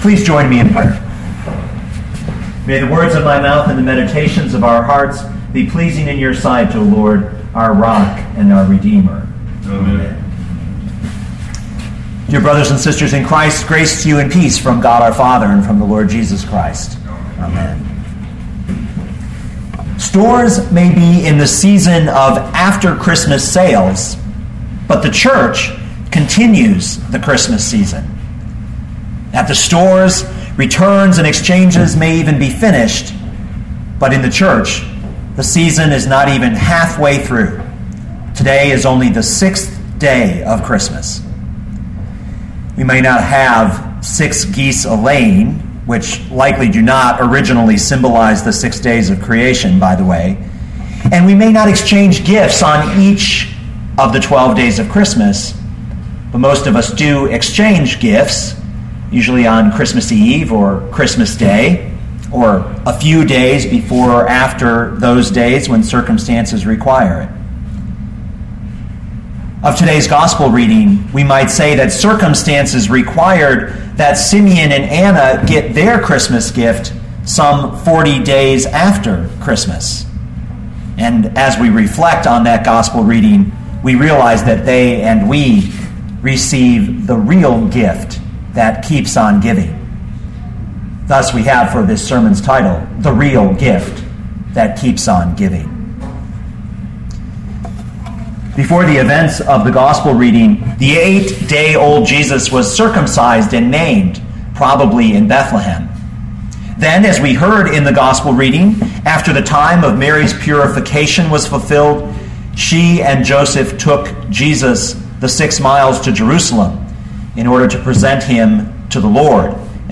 2012 Luke 2:22-40 Listen to the sermon with the player below, or, download the audio.